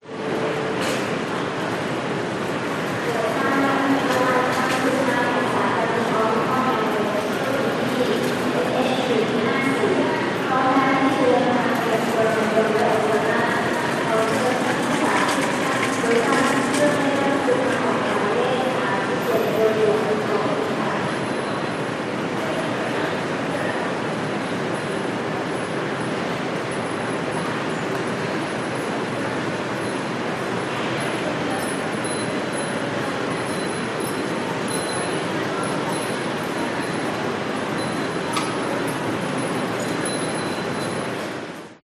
Звуки аэропорта, атмосфера
Аэропорт Бангкока зал отправления гул голосов громкоговоритель система оповещения кондиционер Таиланд